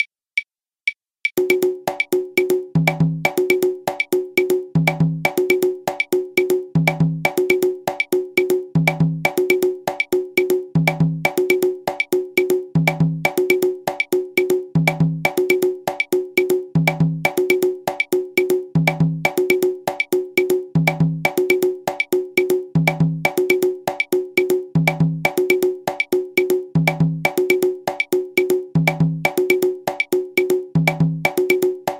accompagné par une clave rumba 3/2
Mozambique (conga 1)
mozambique_conga1.mp3